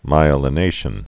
(mīə-lə-nāshən) also my·e·li·ni·za·tion (-nĭ-zāshən)